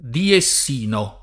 pidieSS&no]) agg. e s. m. — dalla sigla P.D.S. [p& ddi $SSe] del Partito Democratico della Sinistra (1991-98) — dal 1998 al 2007, essendo stata sostituita quella sigla dal semplice D.S. [di $SSe] (Democratici di Sinistra), diessino [